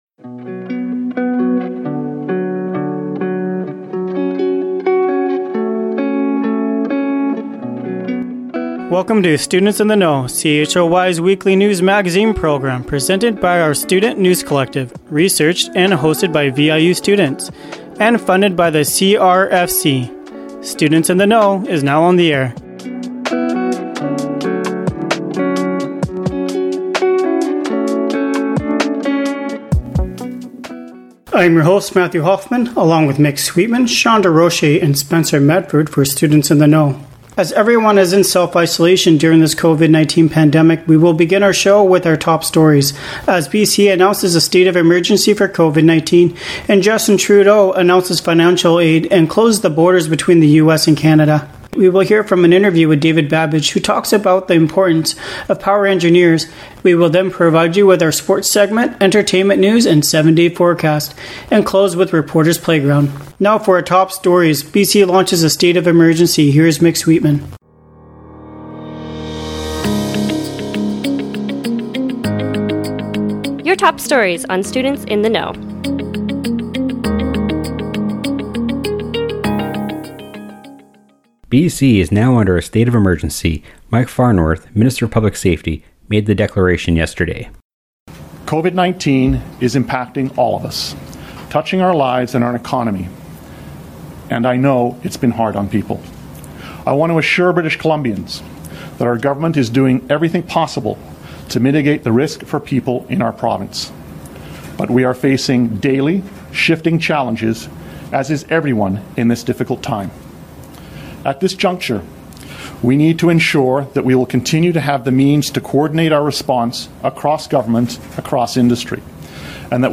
Short version with no music. BC launches a state of emergency for COVID-19